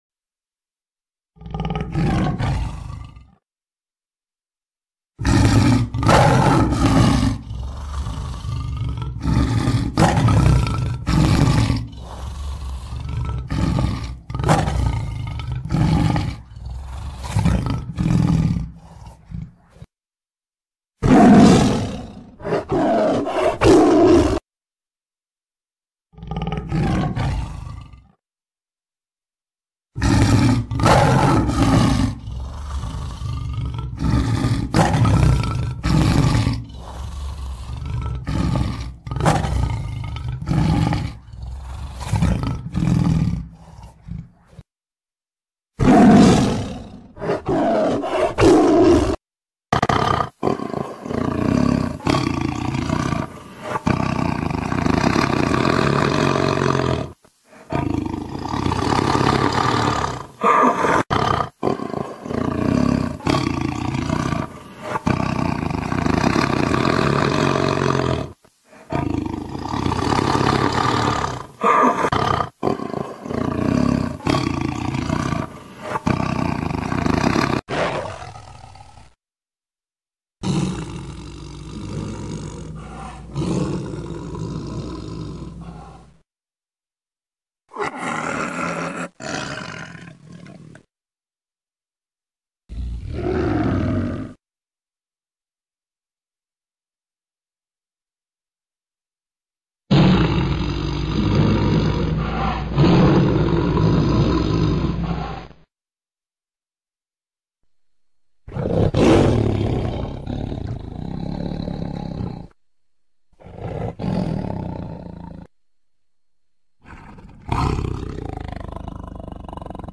دانلود صدای ببر 2 از ساعد نیوز با لینک مستقیم و کیفیت بالا
جلوه های صوتی